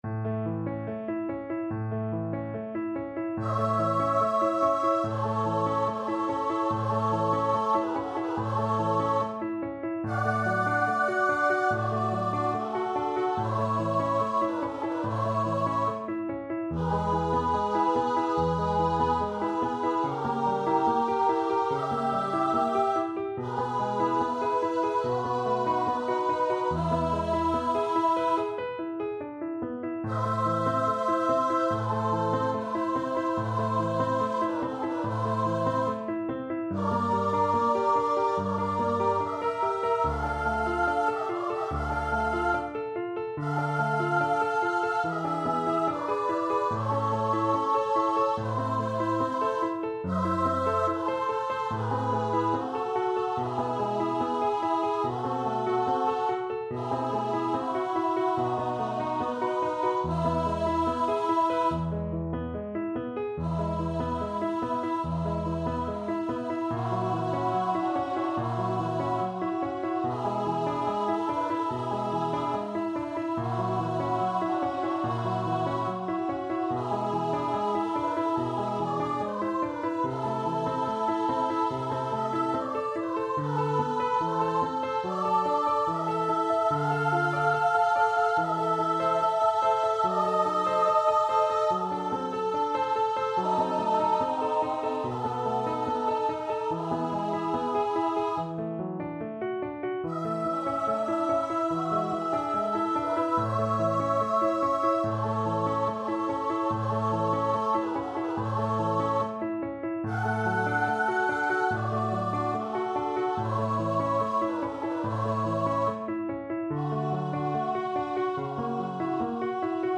Free Sheet music for Vocal Duet
4/4 (View more 4/4 Music)
A major (Sounding Pitch) (View more A major Music for Vocal Duet )
Andante =72
Classical (View more Classical Vocal Duet Music)